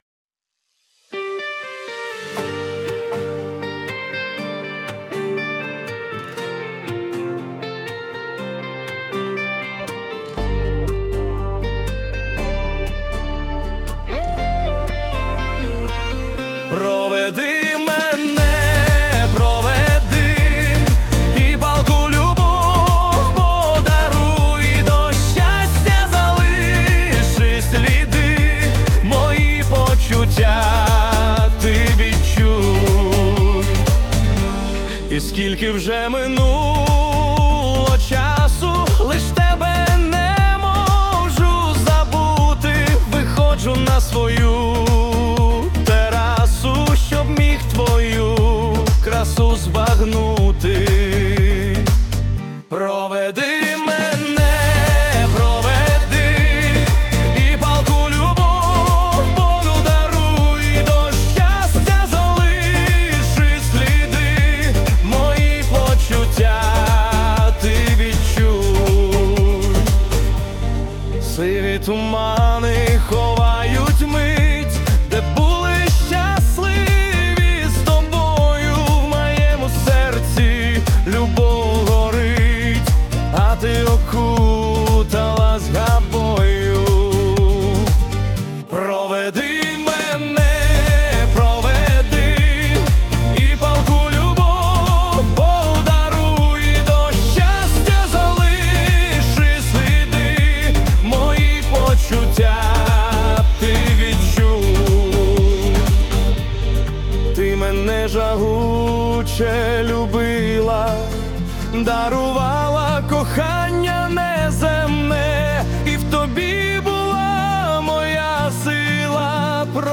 СТИЛЬОВІ ЖАНРИ: Ліричний
Гарна, зворушлива пісня. яка так мелодійно співається. 16 12 give_rose